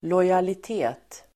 Ladda ner uttalet
Uttal: [låjalit'e:t]